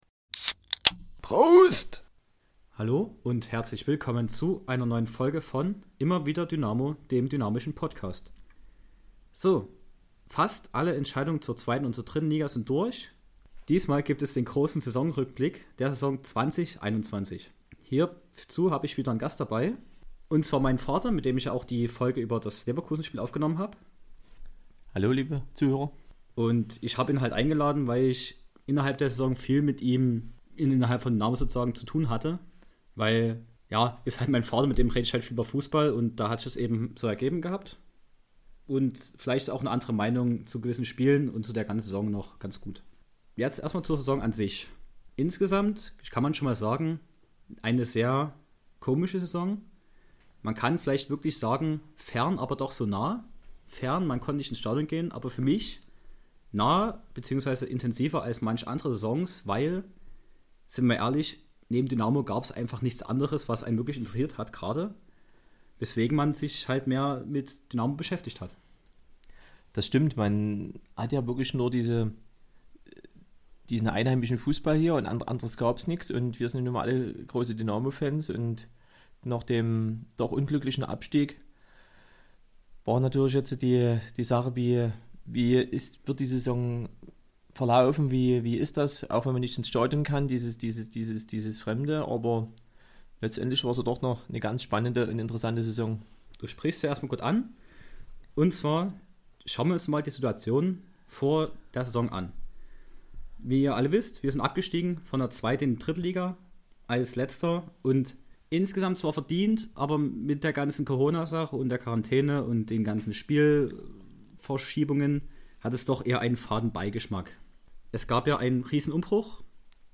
Eine grandiose Saison hat ihr Ende gefunden, weswegen es Zeit für einen Rückblick ist. Ich gehe mit einem Gast alle Spiele und die gesamte Saison durch.